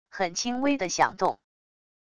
很轻微的响动wav音频